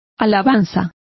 Complete with pronunciation of the translation of applauses.